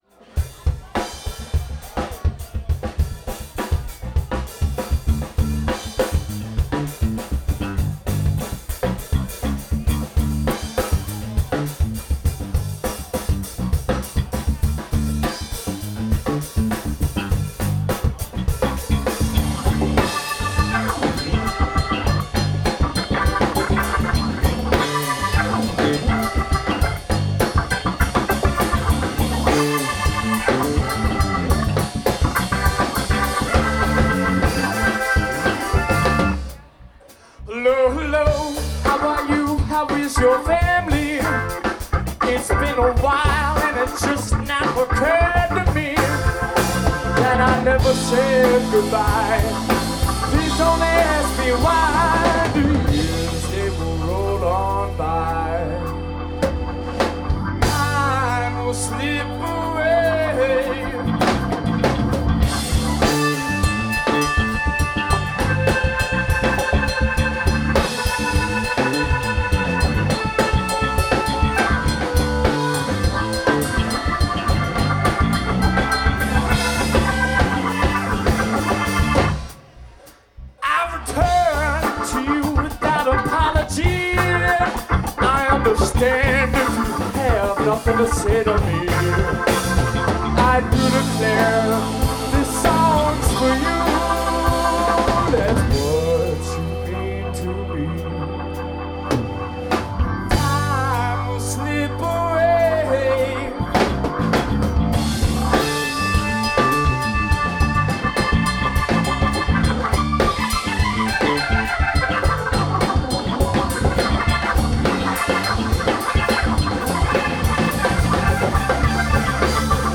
a band from Asheville, NC
recorded at a small festival in Snow Camp, NC